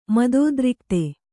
♪ madōdrikte